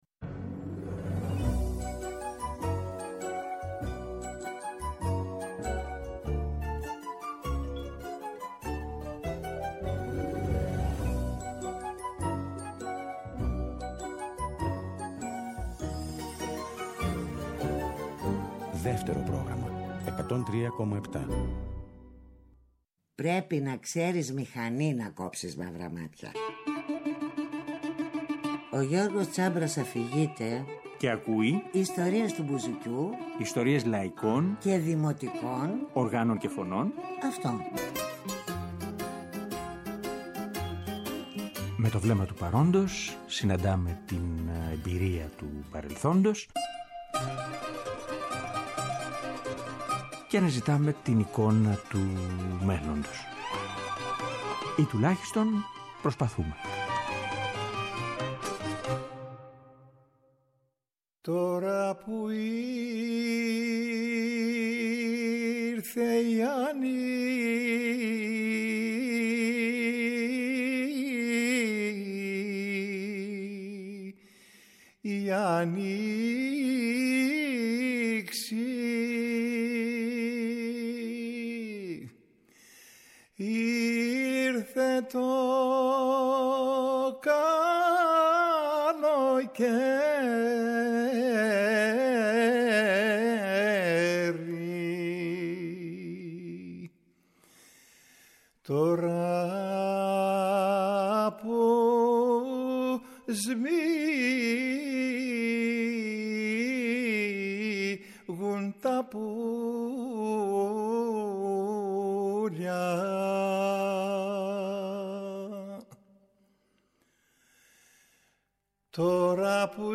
Ακούγοντας ηχογραφήσεις με τον Χρόνη
Ακούγοντας ηχογραφήσεις με τον Χρόνη Αηδονίδη, μιλάμε για τη σχέση του καθένα από τους τρείς μαζί του και τις μνήμες του από αυτόν. Και παράλληλα, αναζητούμε τη θέση του υλικού αλλά και της στάσης ζωής που υπερασπίστηκε, στα επόμενα χρόνια.